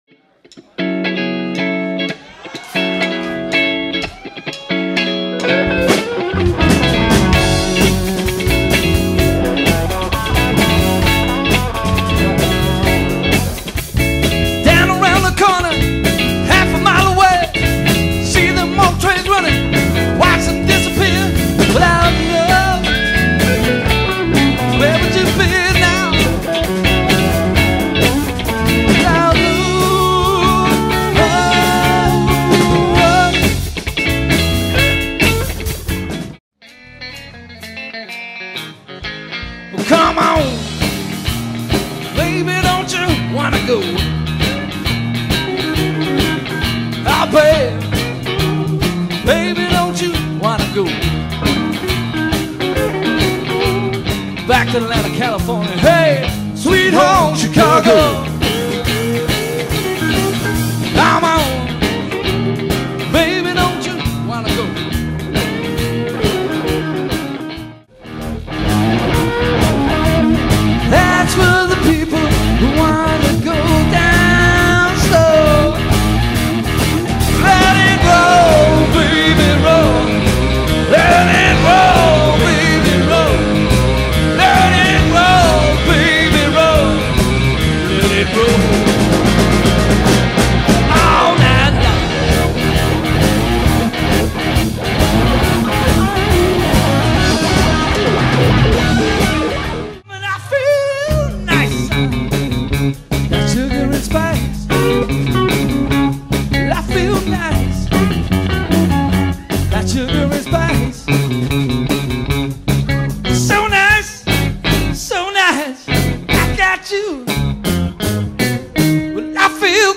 four piece cover band
classic rock and blues tunes
rhythm guitar & vocals
bass & vocals
lead guitar & lead vocals
drums.